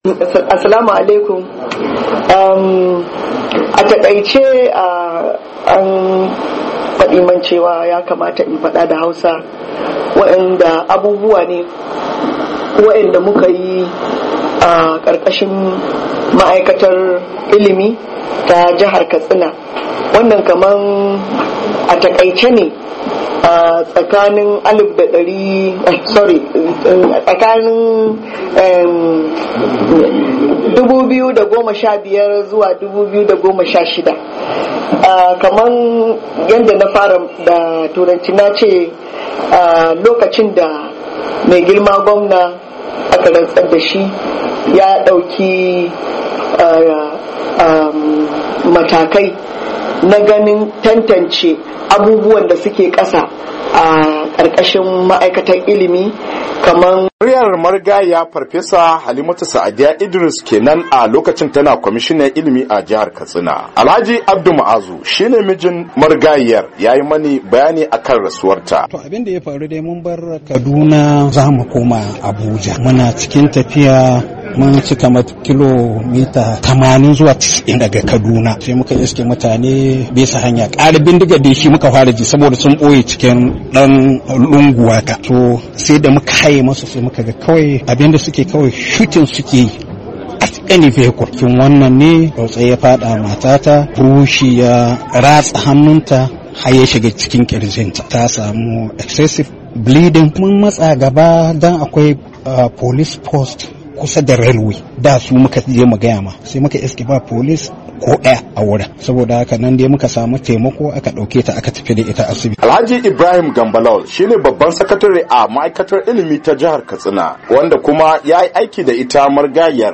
Saurari rahoto